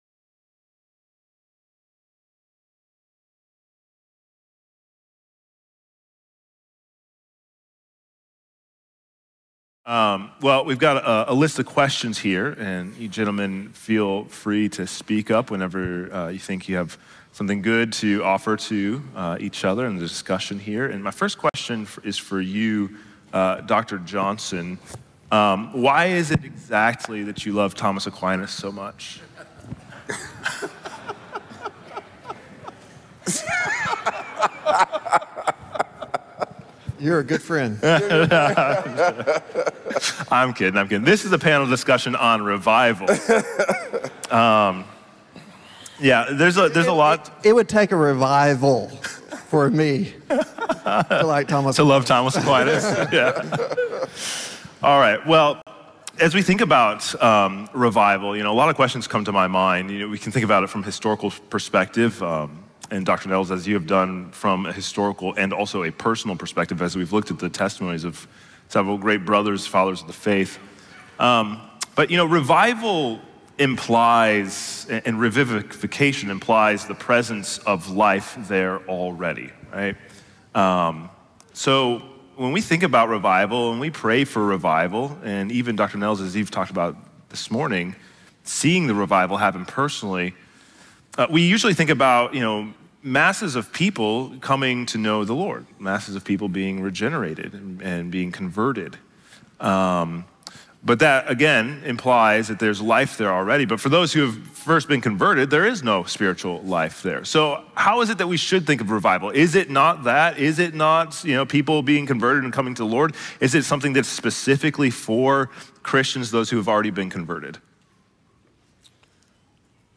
Revive Us, O Lord Panel
2025 at the "Revive Us, O Lord!" 2025 National Founders Conference in Fort Myers, Florida.